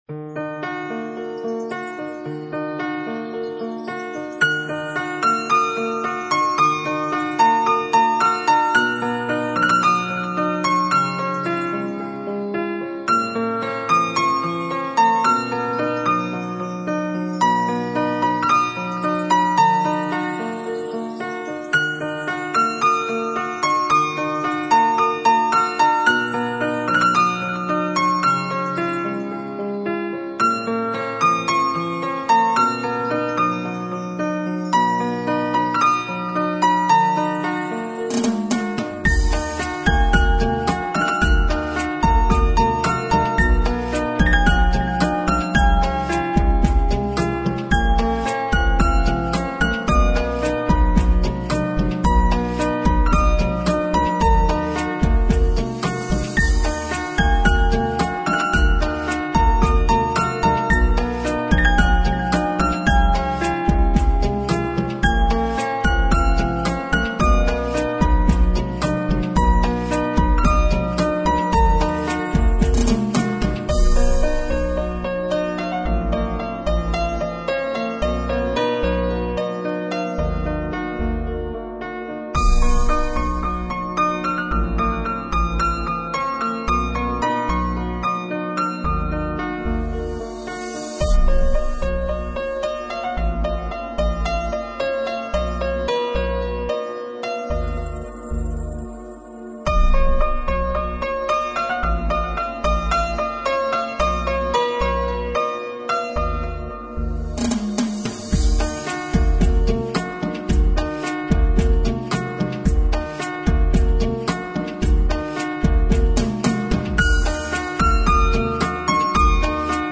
melodiya-vody-piano.mp3